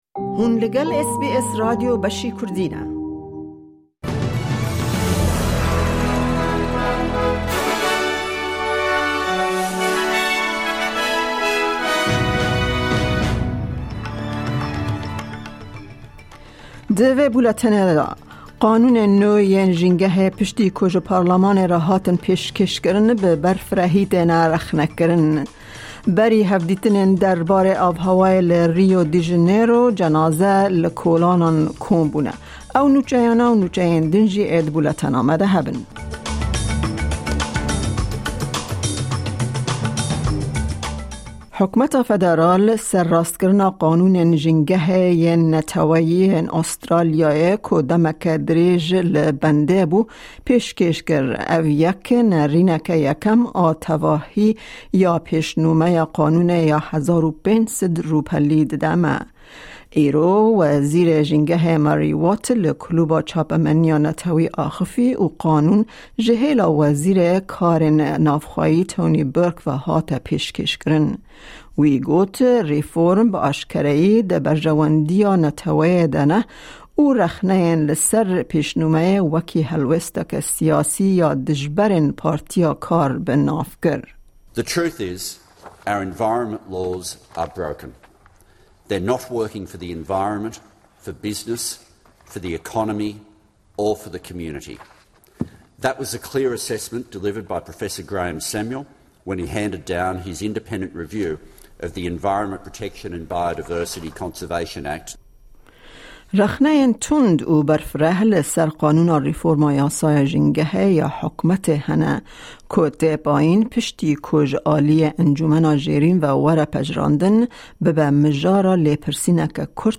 Nûçe